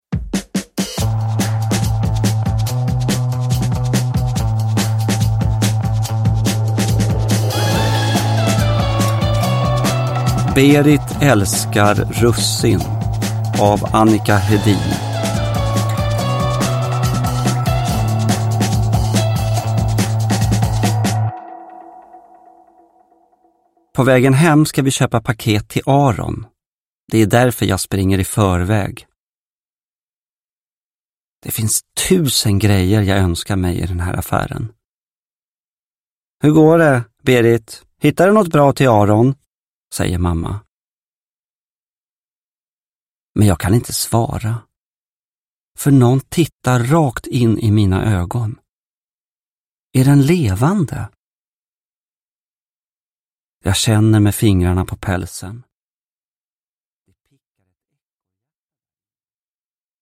Uppläsare: Gustaf Hammarsten